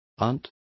Complete with pronunciation of the translation of aunt.